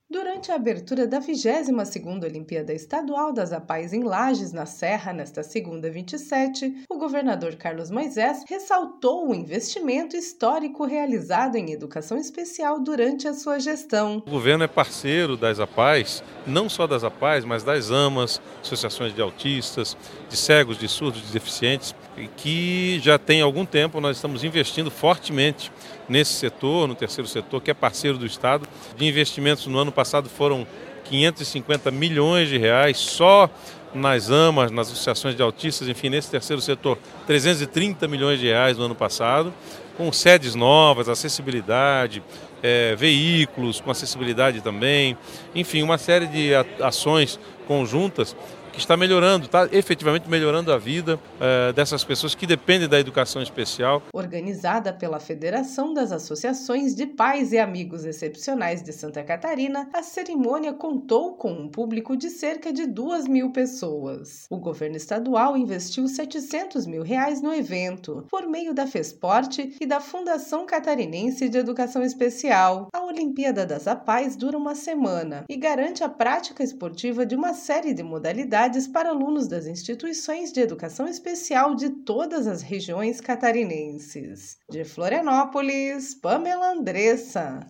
Durante a abertura da 22ª Olimpíada Estadual das Apaes, em Lages, na serra, nesta segunda, 27, o governador Carlos Moisés ressaltou o investimento histórico realizado em educação especial durante sua gestão.